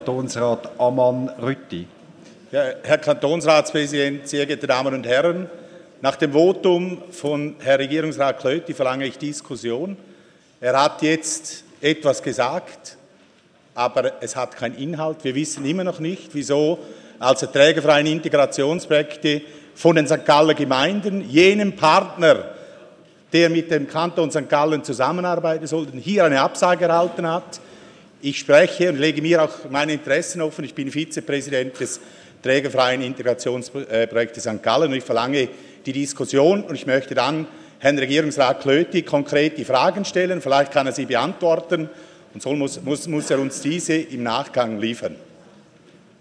14.9.2015Wortmeldung
Session des Kantonsrates vom 14. bis 16. September 2015